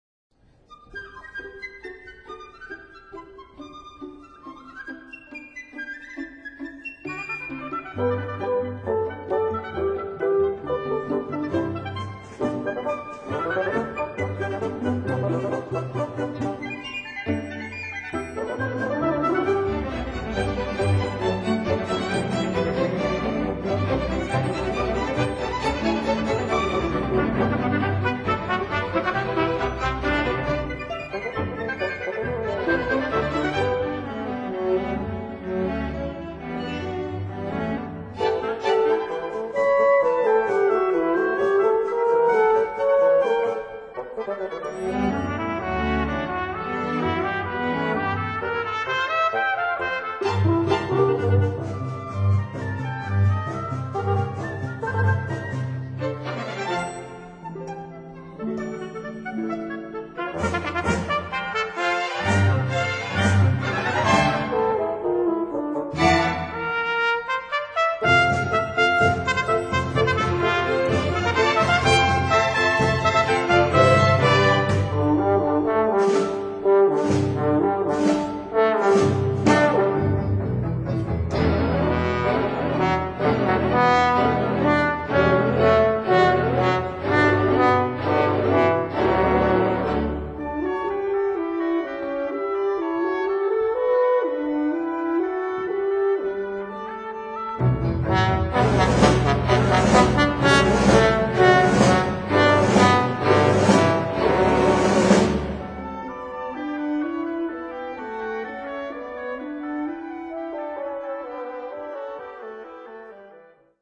Teatro Comunale di Chiaravalle (AN)